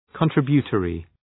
Προφορά
{kən’trıbjə,tɔ:rı}